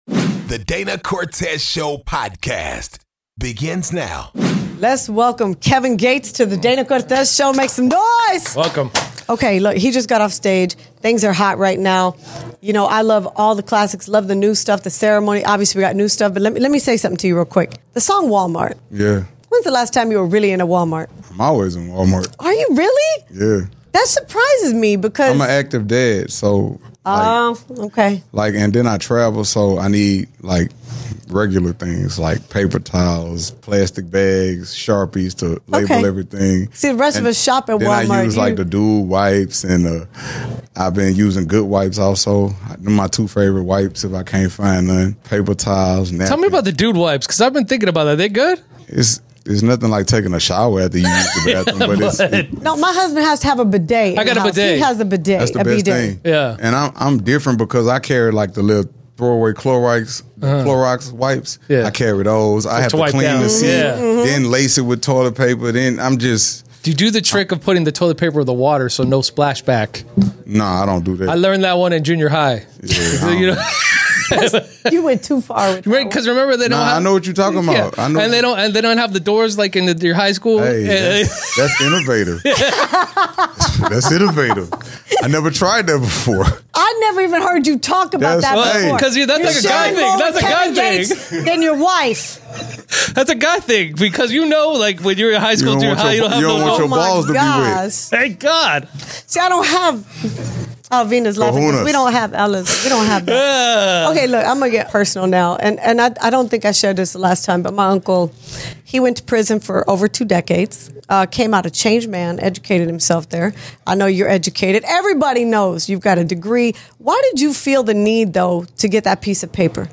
DCS Interviews Kevin Gates